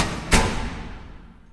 6_redbutton.ogg